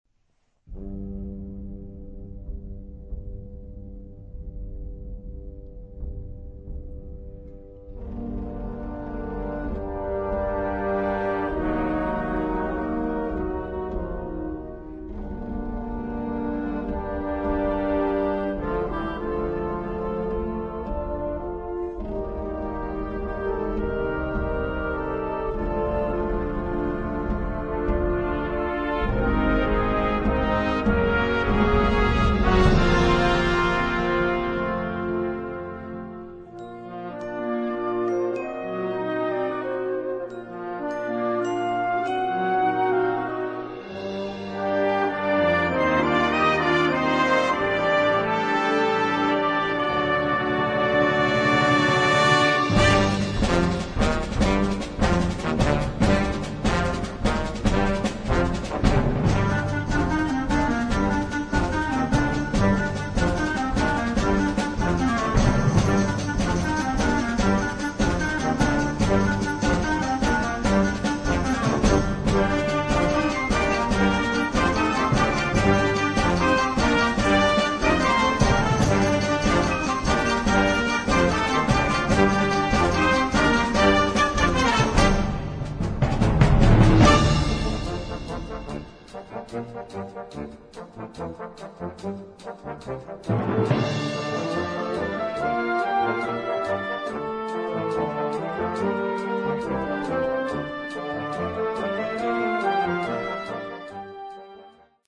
Partitions pour orchestre d'harmonie des jeunes.